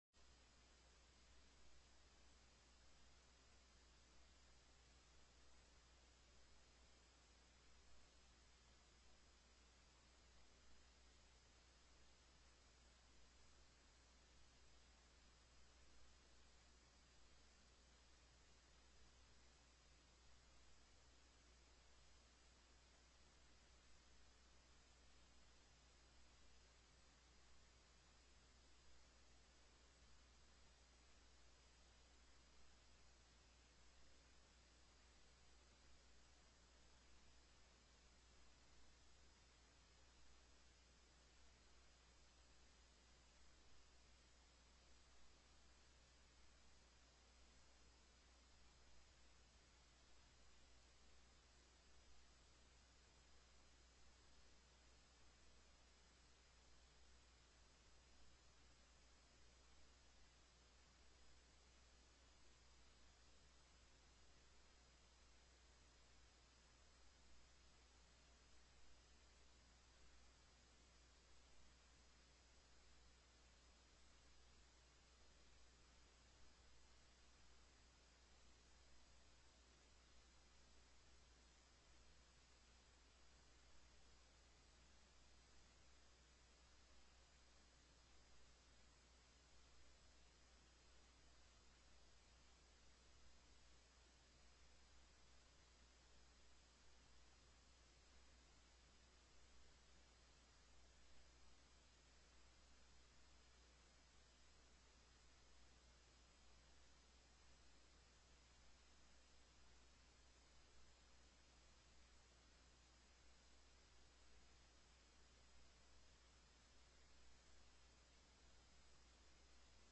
The audio recordings are captured by our records offices as the official record of the meeting and will have more accurate timestamps.
TransCanada Buyout Proposal Presentation